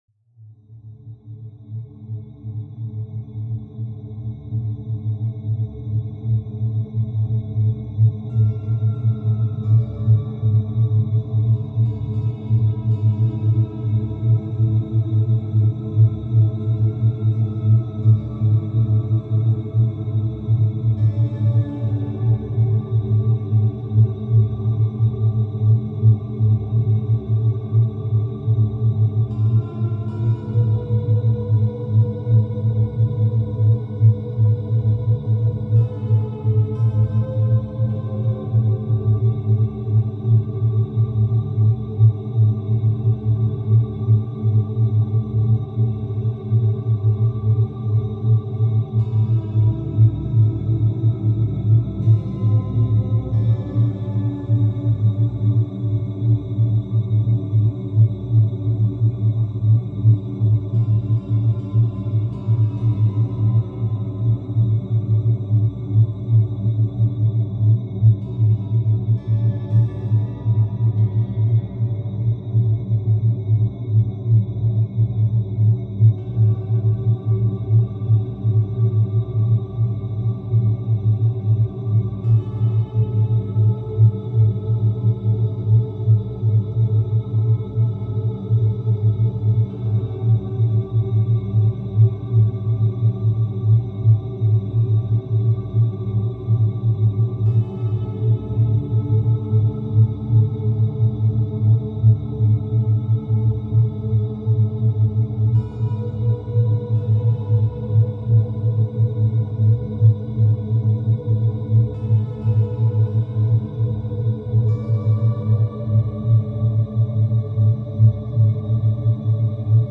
Es beginnt leise.